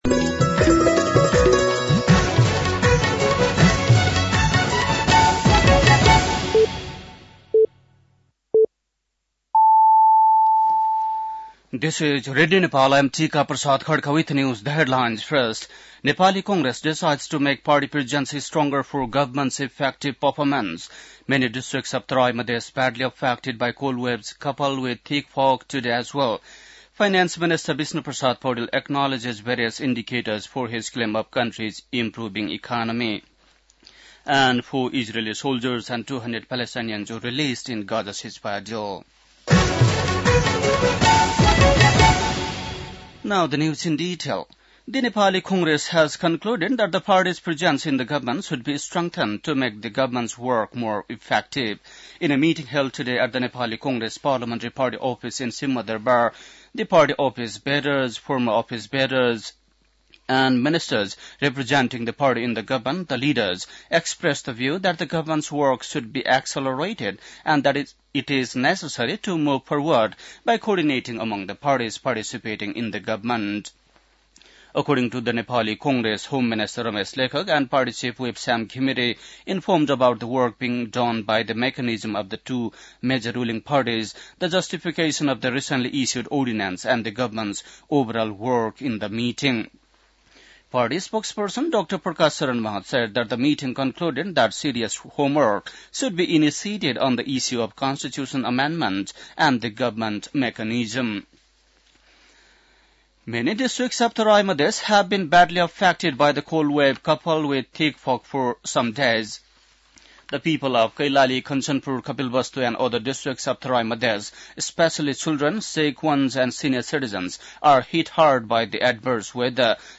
बेलुकी ८ बजेको अङ्ग्रेजी समाचार : १३ माघ , २०८१